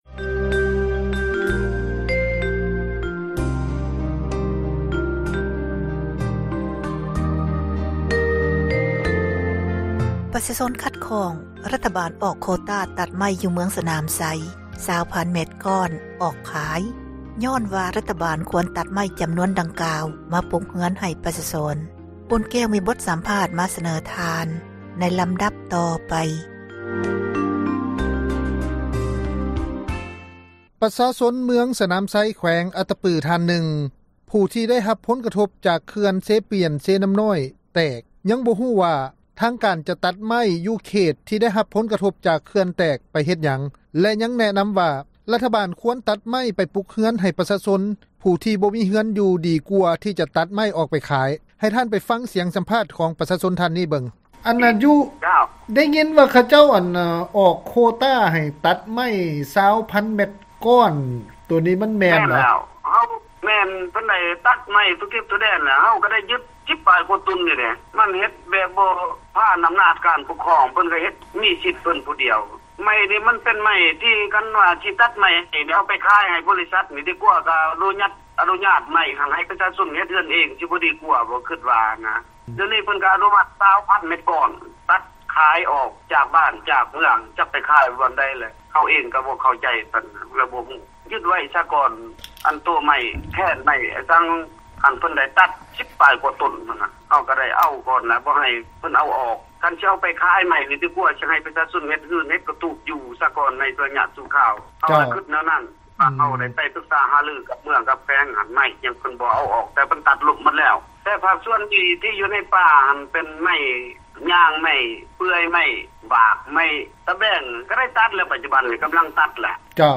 ປະຊາຊົນເມືອງສນາມໄຊ ແຂວງອັດຕະປື ທ່ານນຶ່ງ ທີ່ໄດ້ຮັບຜົນກະທົບ ຈາກ ເຂື່ອນ ເຊປຽນ-ເຊນ້ຳນ້ອຍ ແຕກ ຍັງບໍ່ຮູ້ວ່າ ທາງການ ຈະຕັດໄມ້ ຢູ່ເຂດ ທີ່ໄດ້ຮັບຜົນກະທົບ ຈາກ ເຂື່ອນແຕກ ໄປເຮັດຫຍັງ ແລະ ຍັງແນະນຳວ່າ ຖ້າຣັຖບານ ຈະຕັດໄມ້ ກໍຄວນຕັດມາ ປຸກເຮືອນ ໃຫ້ປະຊາຊົນ ທີ່ບໍ່ມີເຮືອນຢູ່ ດີກວ່າ ທີ່ຈະຕັດອອກ ໄປຂາຍ. ເຊີນທ່ານ ຟັງສຽງສຳພາດ...